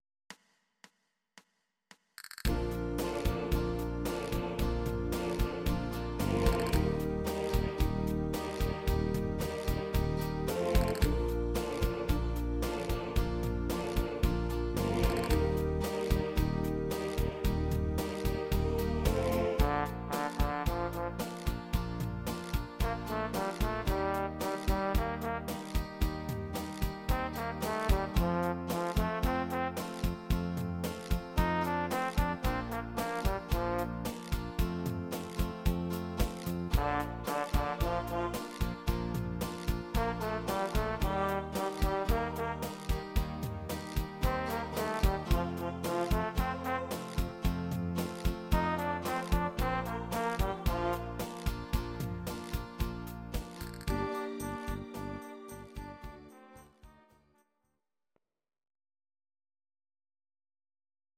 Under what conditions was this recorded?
Demo's played are recordings from our digital arrangements.